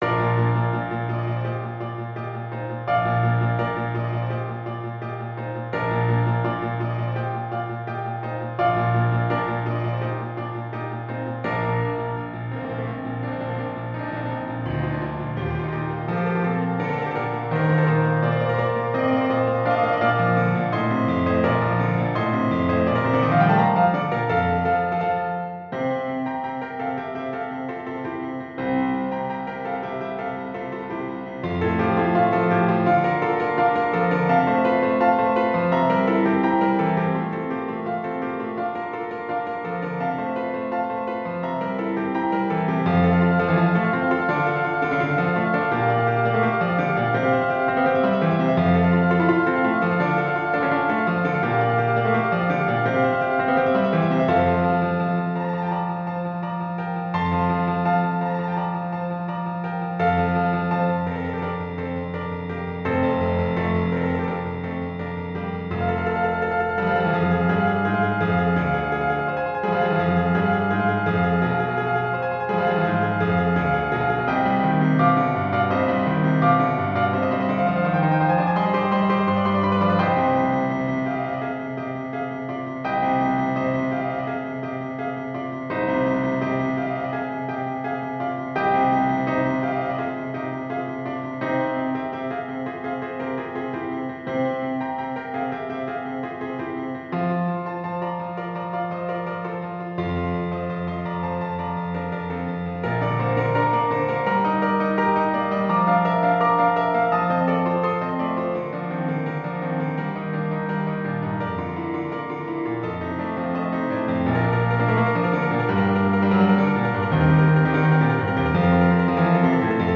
OboesFrench HornsViolin 1Violin 2ViolaCellos & Basses
Classical (View more Classical Orchestra Music)